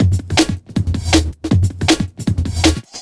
JUNGLE5-L.wav